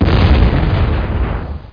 home *** CD-ROM | disk | FTP | other *** search / OK PC 58 / OKPc.iso / ROTT / HUNTBGIN.WAD / C_EXPLOD ( .mp3 ) < prev next > Creative Voice | 1995-01-17 | 19KB | 1 channel | 10,869.6 sample rate | 1 second
C_EXPLOD.mp3